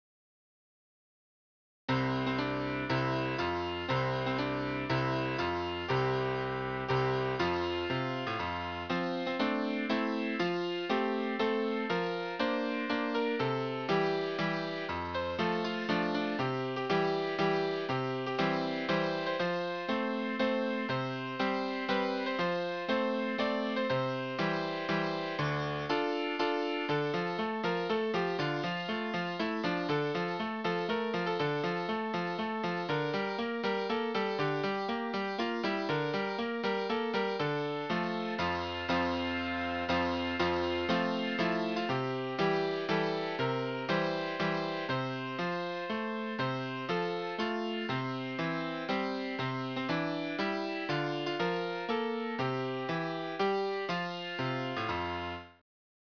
校歌ＢＧＭ